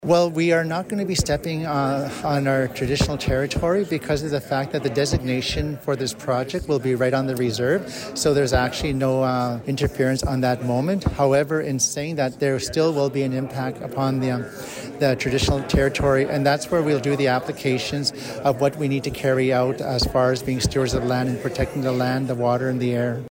Chief Powder tells CFWE that this development will help protect their land and water while promoting growth for generations to come.